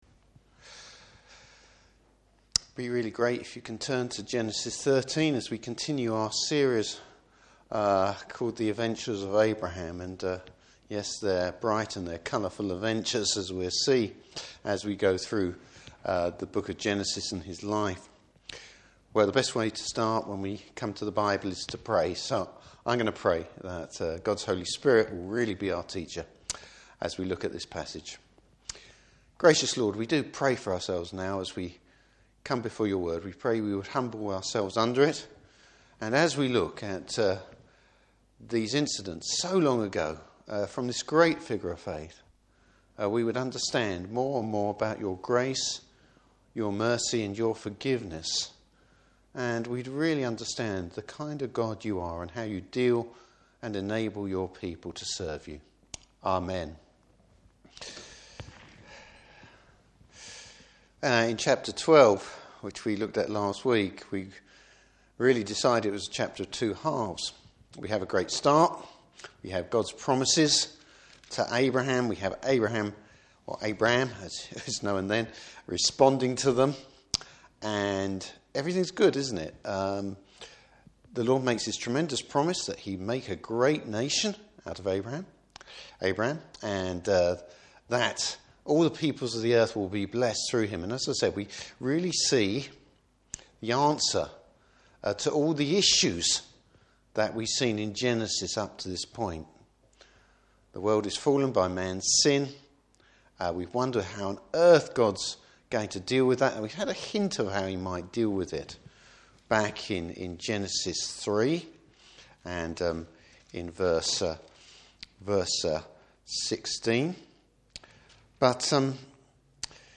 Service Type: Evening Service How Abram’s faith is a product of God’s grace.